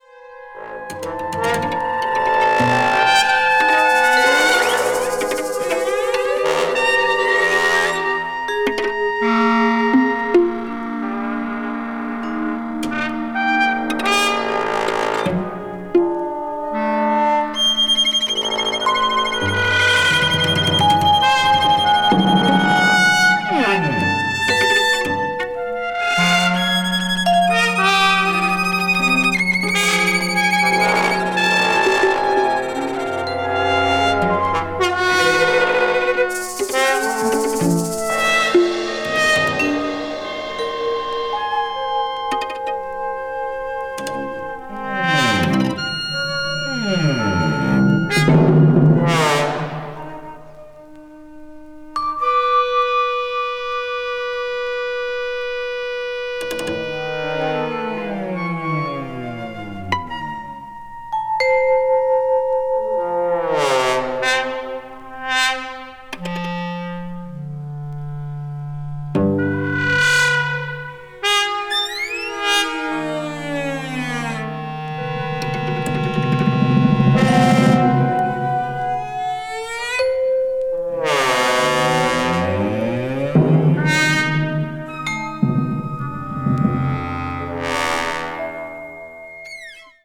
for 10 Instruments
20th century   avant-garde   contemporary   post modern